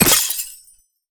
ice_spell_impact_shatter_06.wav